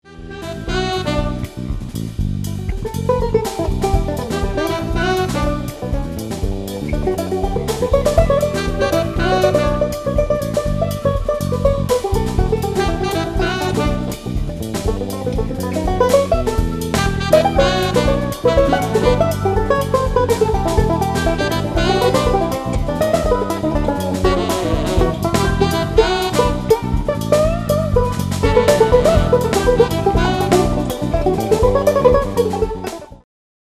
bass
horns
percussion
modern jazz